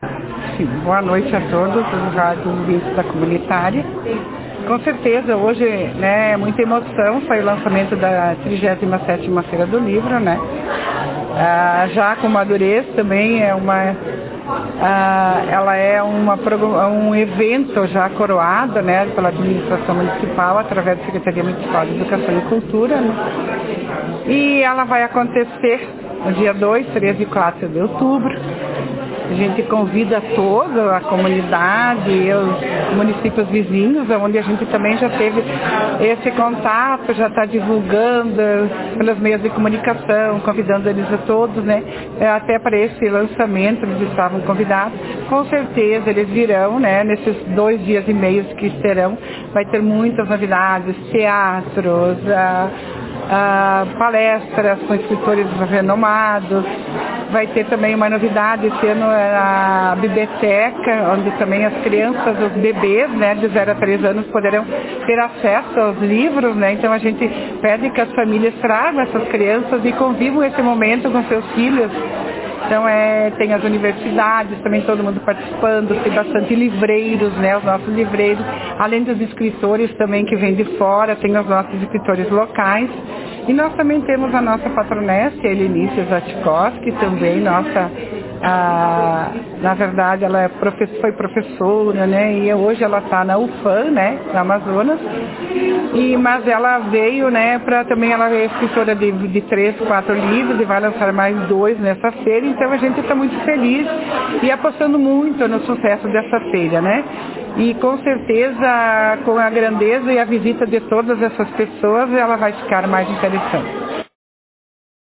Em entrevista para a Rádio Comunitária, Carmen falou um pouco sobre a feira: